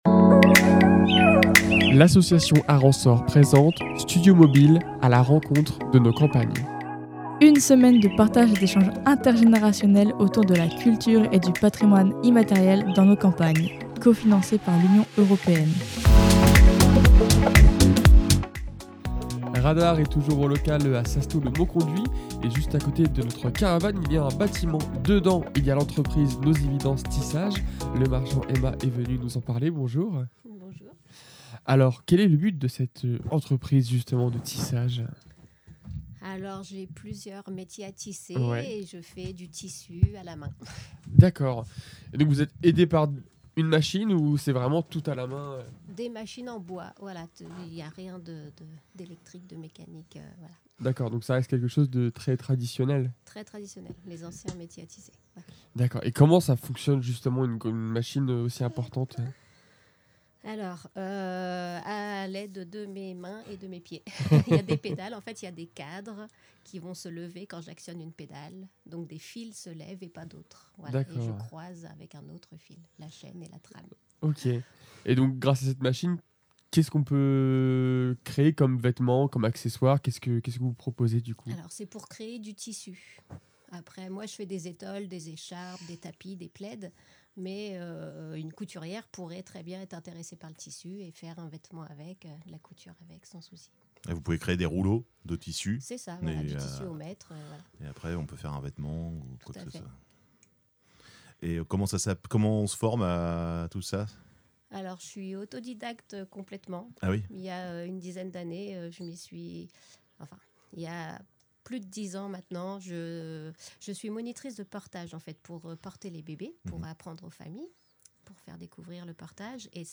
Le studio mobile a pour objectif de tisser du lien, et ça tombe bien : l’entreprise Nos Évidences Tissage nous a rejoints dans la caravane pour nous expliquer le métier à tisser et les ateliers proposés sur place.